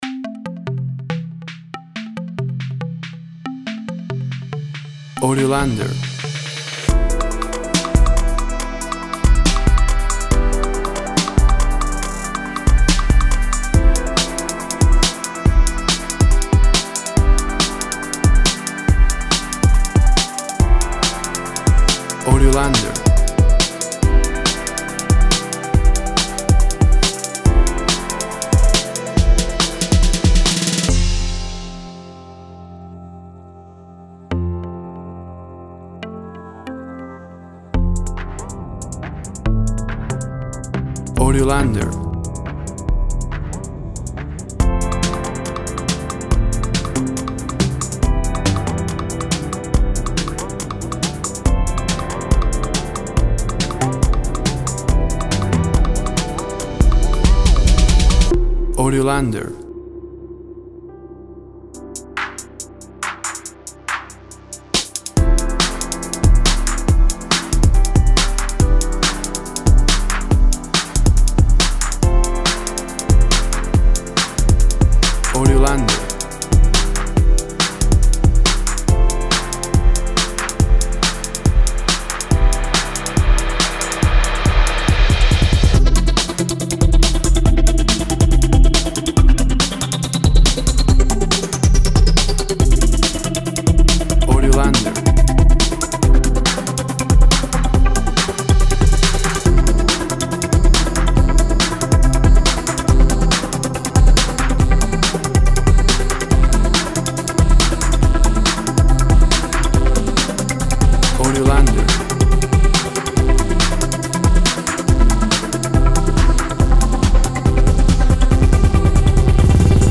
Electro Pop.
Tempo (BPM) 135